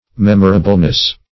Mem"o*ra*ble*ness, n. -- Mem"o*ra*bly, adv.
memorableness.mp3